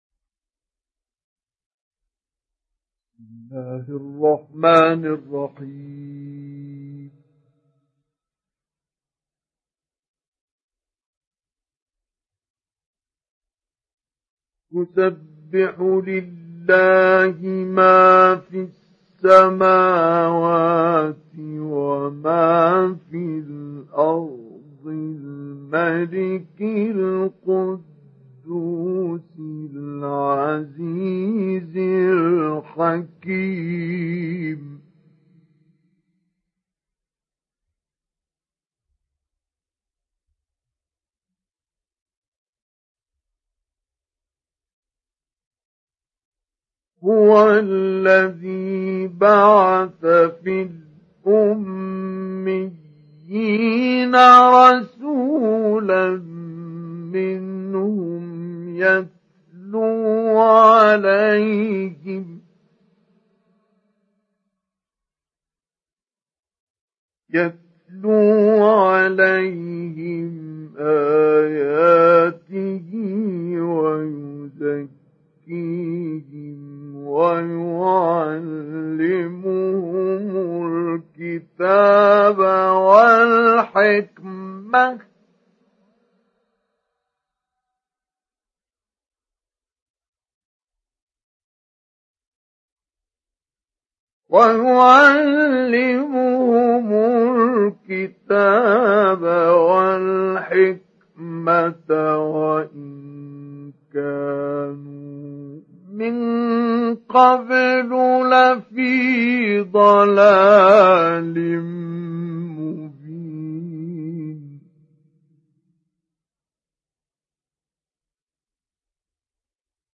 Download Surat Al Jumaa Mustafa Ismail Mujawwad